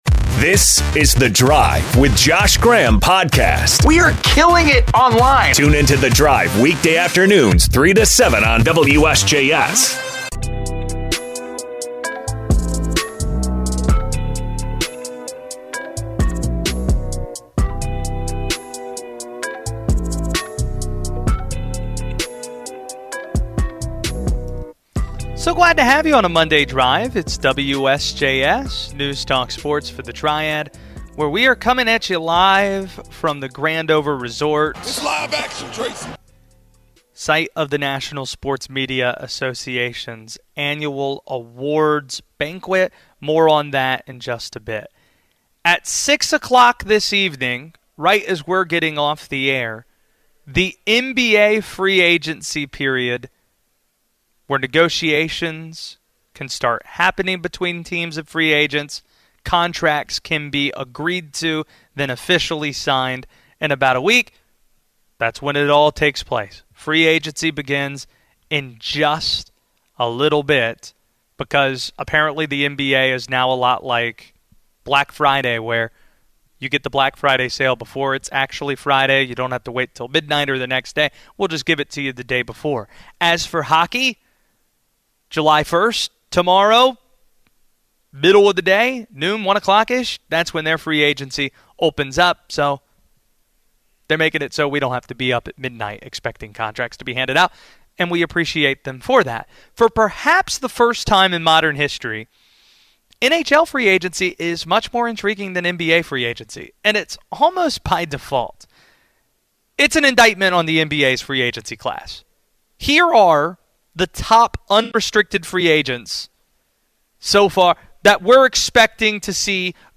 Live from the NSMA awards banquet